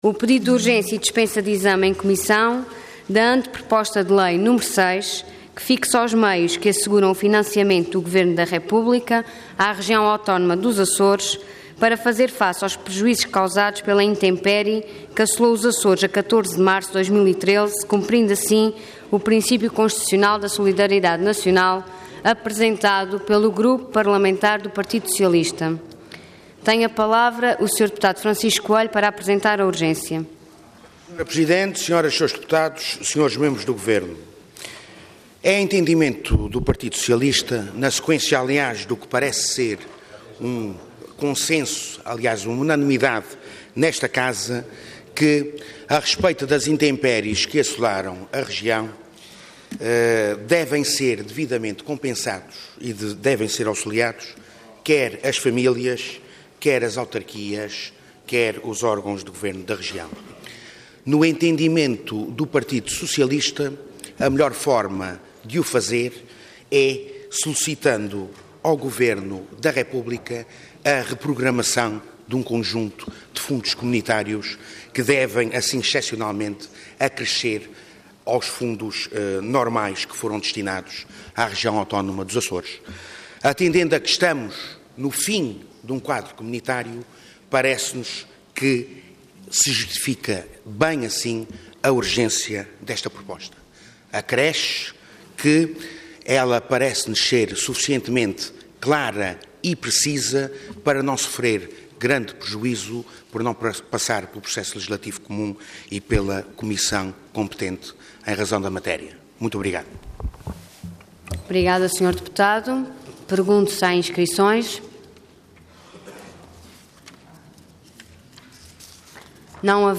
Website da Assembleia Legislativa da Região Autónoma dos Açores
Intervenção Pedido de urgência seguido de debate Orador Sérgio Ávila Cargo Deputado Entidade ALRAA